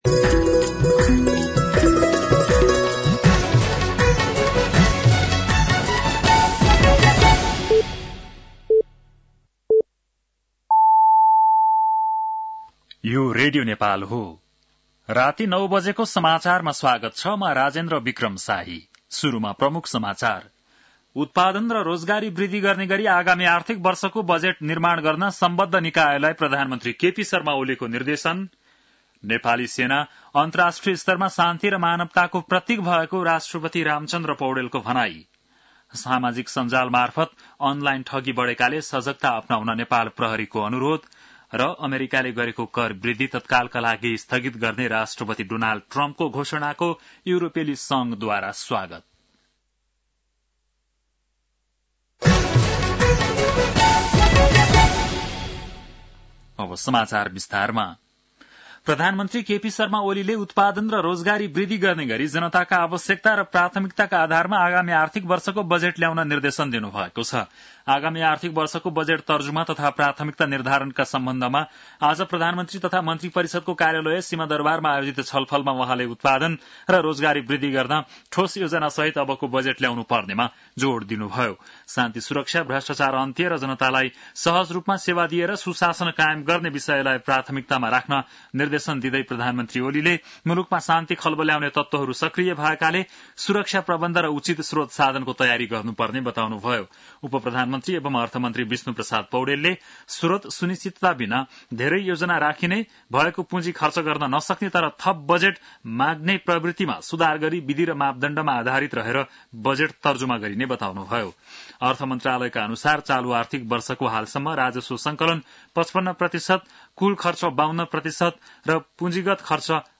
बेलुकी ९ बजेको नेपाली समाचार : २८ चैत , २०८१